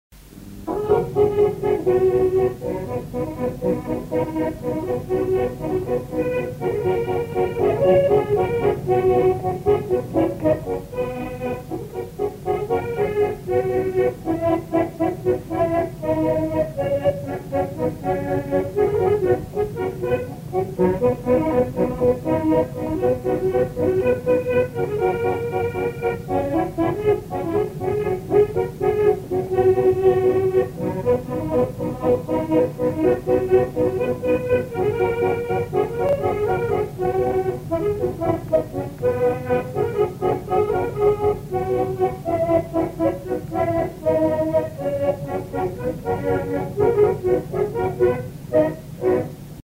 Polka ou marche
Aire culturelle : Haut-Agenais
Lieu : Monclar d'Agenais
Genre : morceau instrumental
Instrument de musique : accordéon diatonique